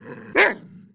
monster / stray_dog / attack_1.wav